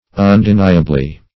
Undeniably \Un`de*ni"a*bly\, adv.